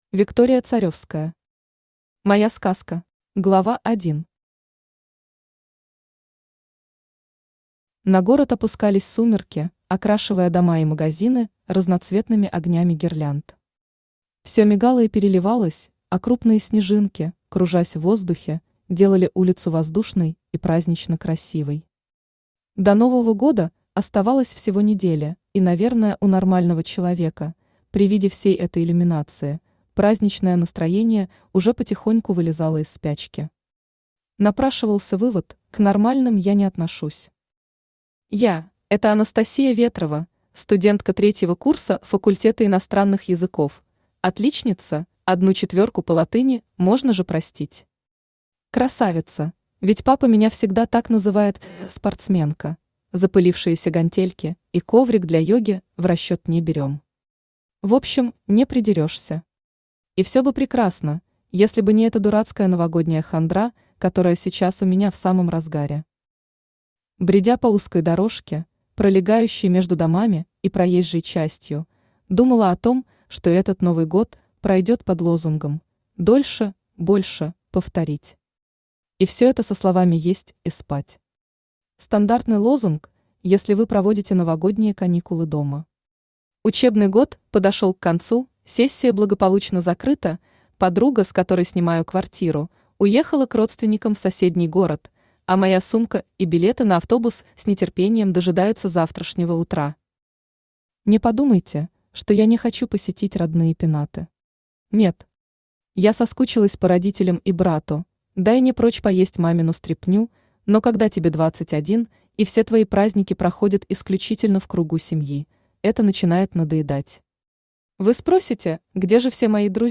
Аудиокнига Моя Сказка | Библиотека аудиокниг
Aудиокнига Моя Сказка Автор Виктория Царевская Читает аудиокнигу Авточтец ЛитРес.